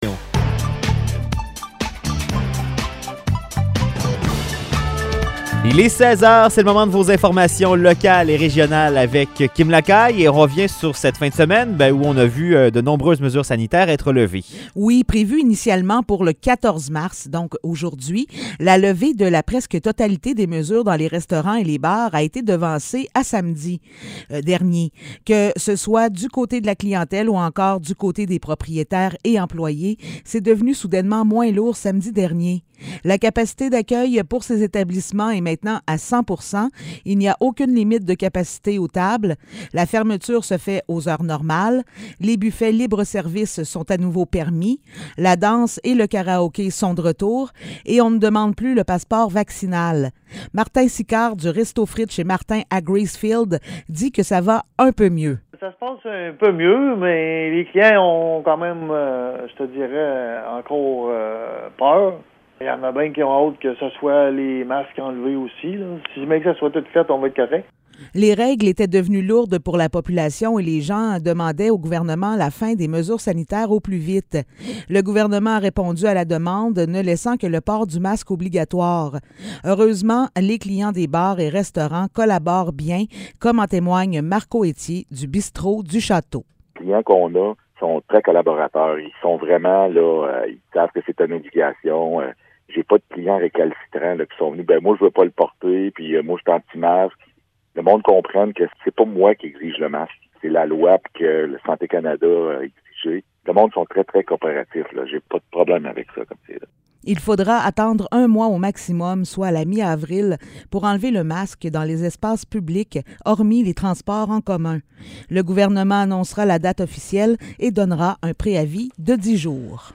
Nouvelles locales - 14 mars 2022 - 16 h